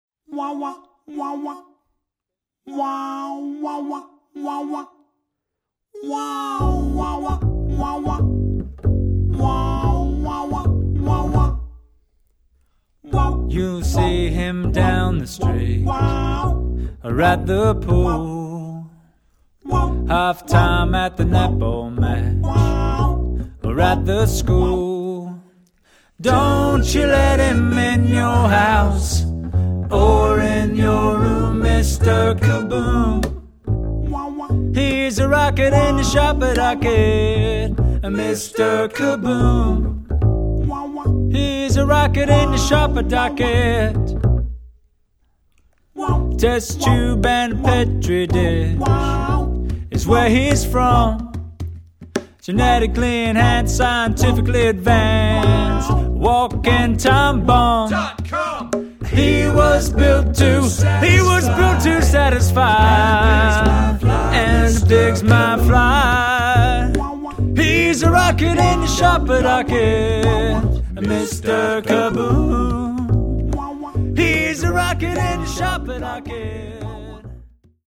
Recorded at Newmarket Studios